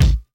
Kick (Chicago).wav